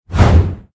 CHQ_VP_swipe.ogg